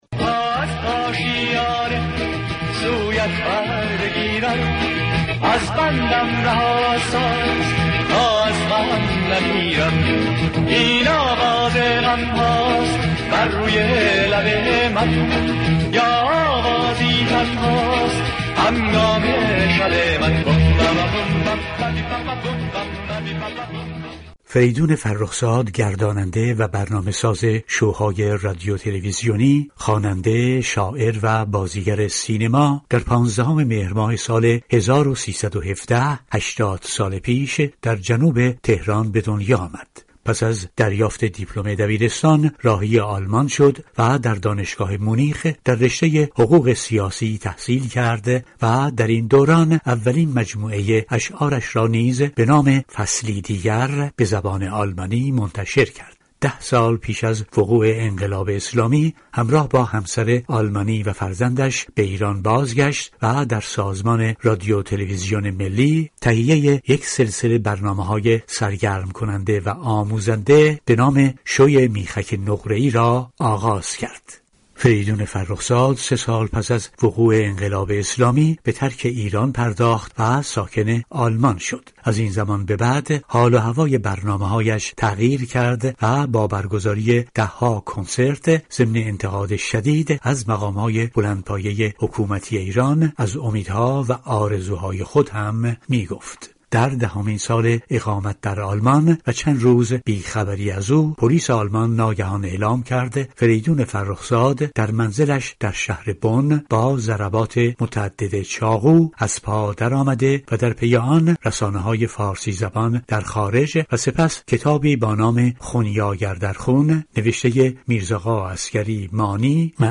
گزارش رادیویی درباره هشتادمین سالروز تولد فریدون فرخزاد